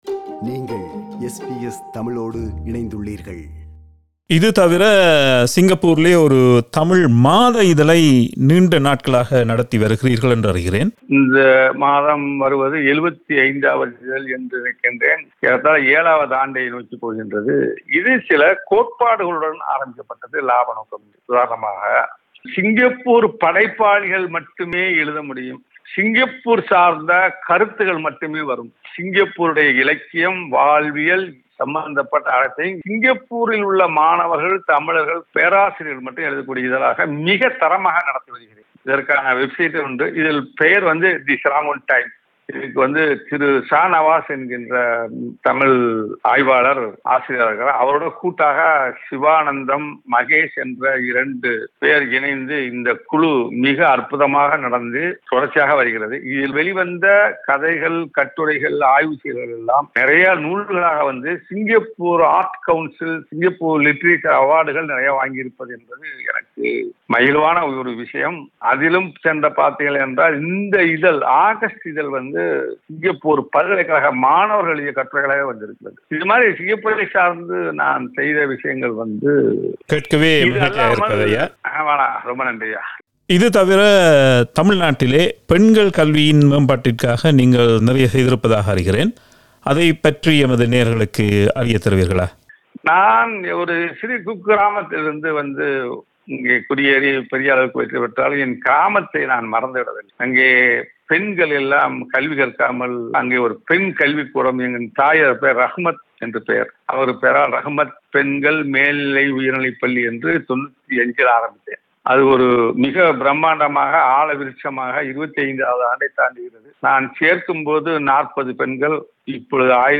This is the concluding part of the two-part interview.